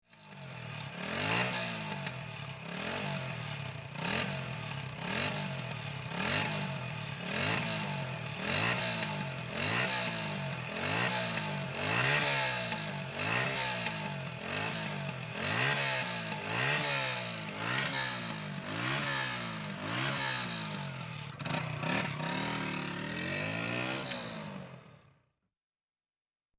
moto_engengant_marxant.mp3